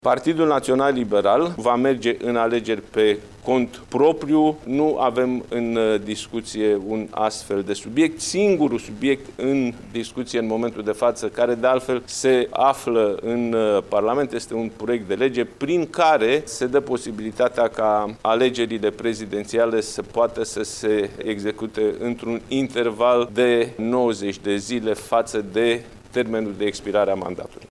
La viitoarele alegeri din acest an, PNL nu va face alianță electorală cu niciun partid, a spus președintele partidului, Nicolae Ciucă, în cadrul unei conferințe de presă.